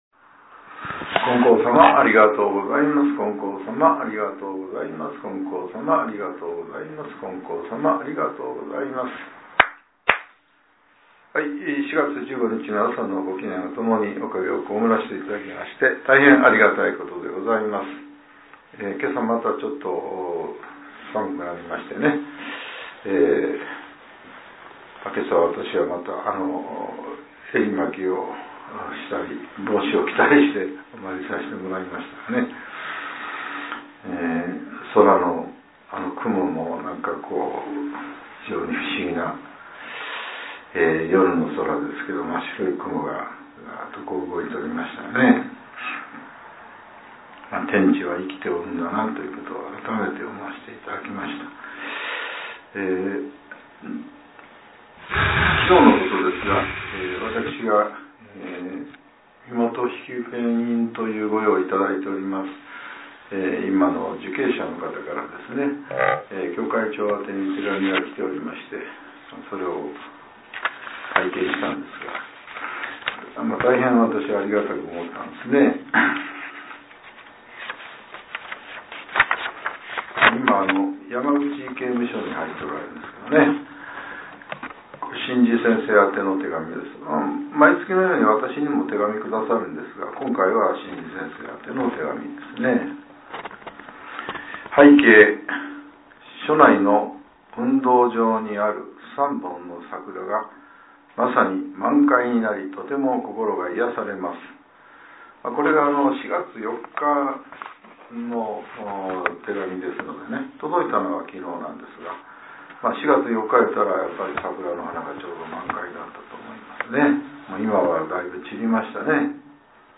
令和７年４月１５日（朝）のお話が、音声ブログとして更新されています。